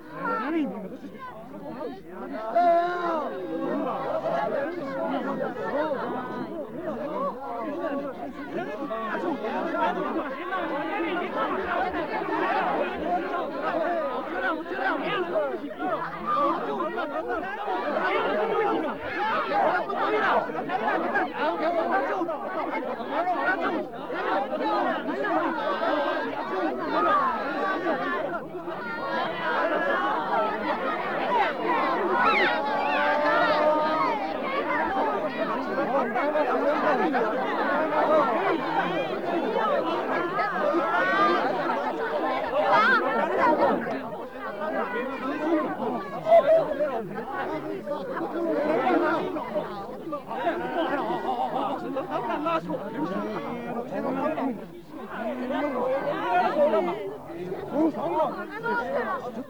0009_循环音_行人.ogg